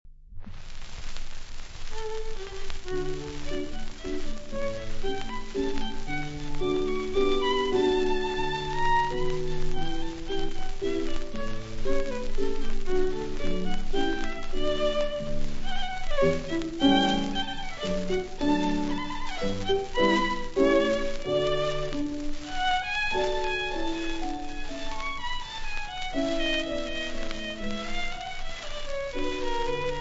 pianoforte
violino